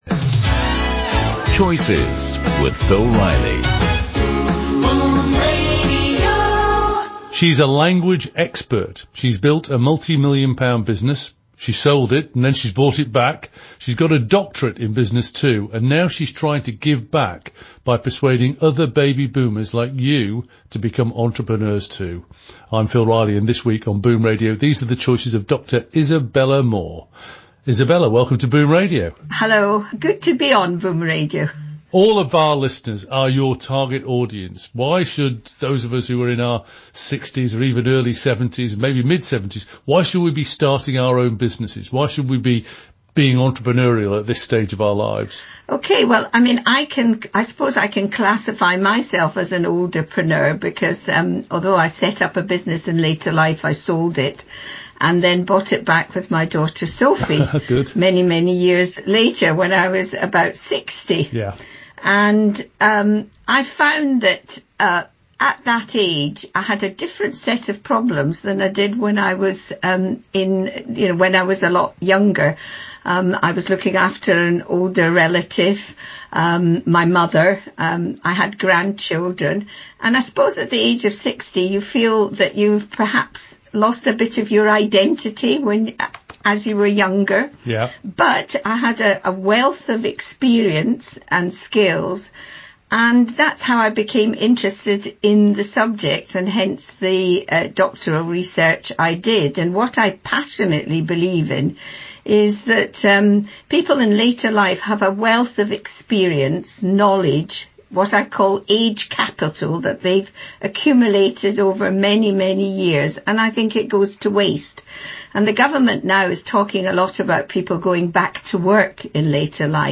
Boom Radio is a commercial radio station run by Baby Boomers for Baby Boomers.